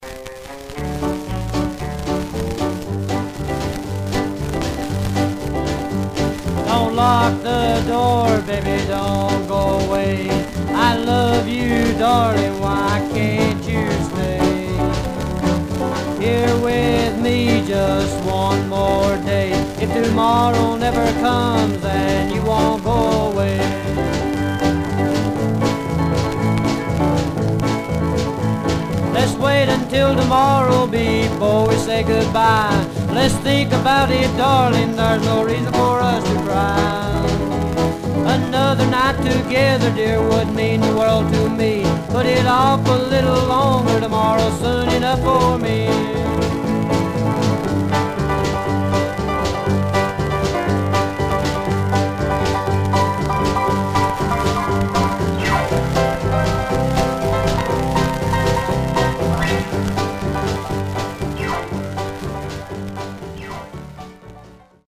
2 Sided Bopper Condition: VG
Condition Surface noise/wear Stereo/mono Mono
Country